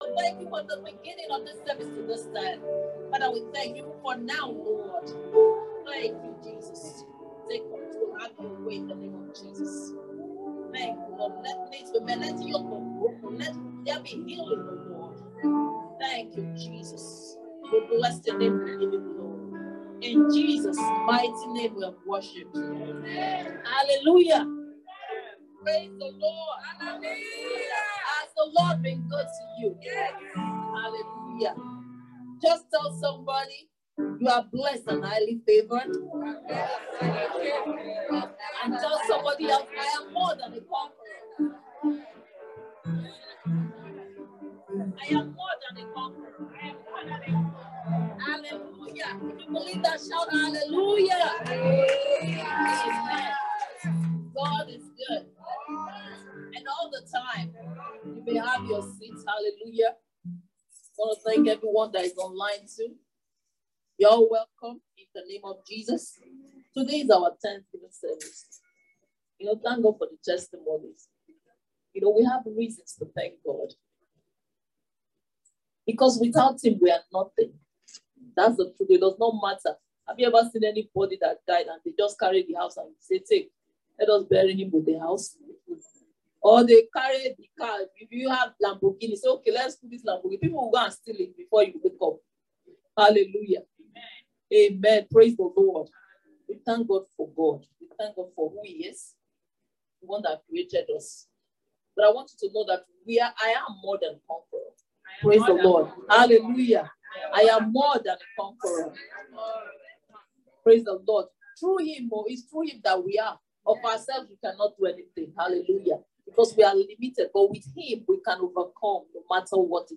February-2022-Thanksgiving-Service.mp3